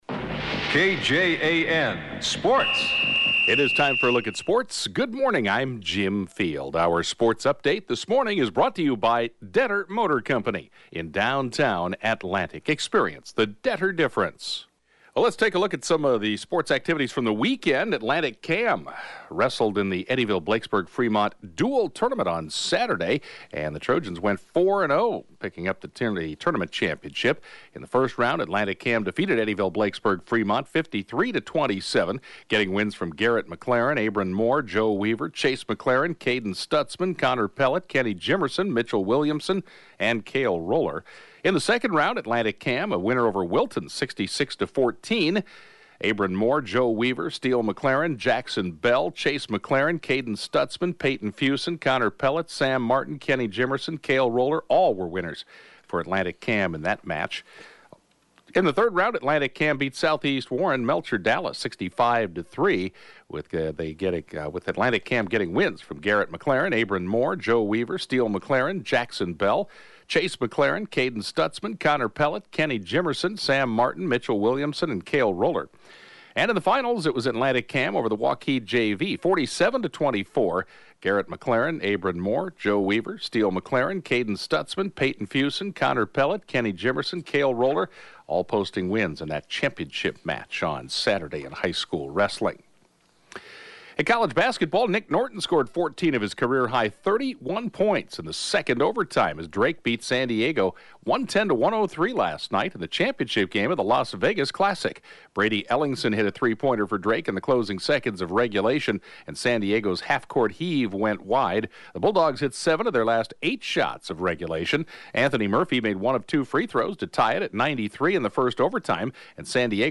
The 7:20-a.m. Sportscast